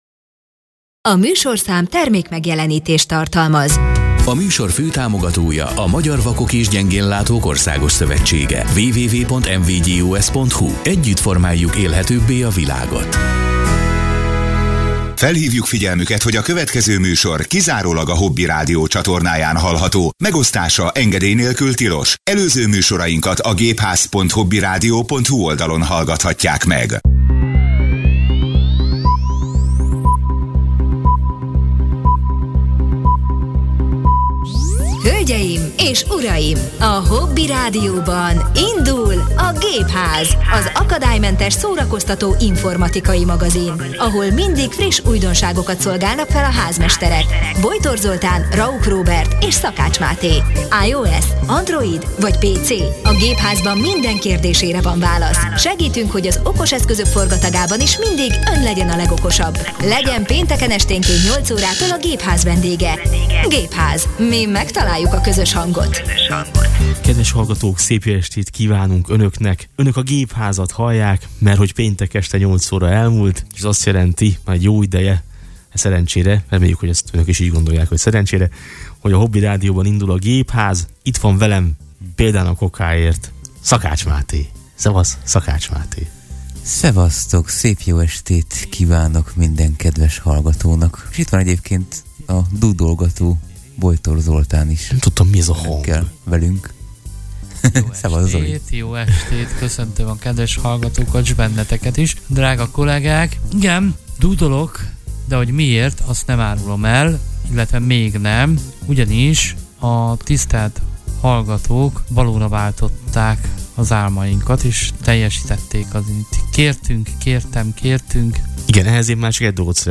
Műsorunk elején a hallgatói levelek mellett olyan alkotásokat hallgattunk meg, amelyeket hallgatóink számunkra készítettek az előző adásban bemutatott, zenegeneráló mesterséges intelligencia segítségével.